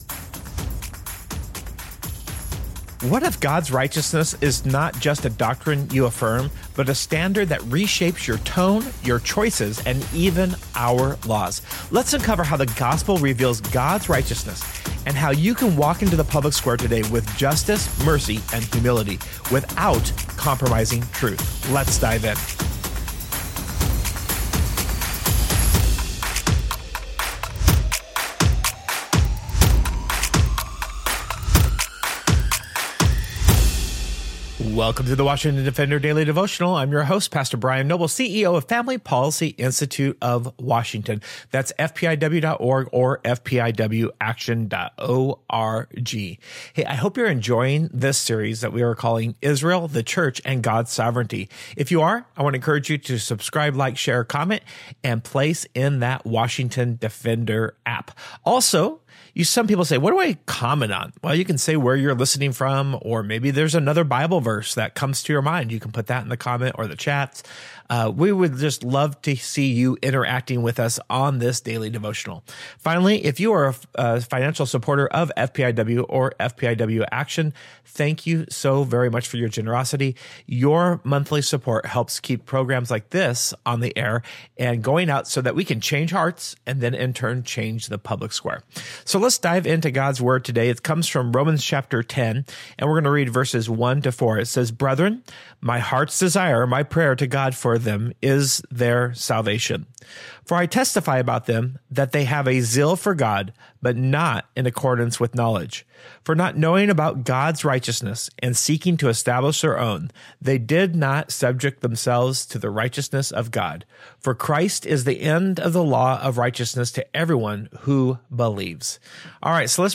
In this devotional, we’ll uncover how the gospel reveals God’s righteousness and how you can walk into the public square today with justice, mercy, and humility—without compromising truth.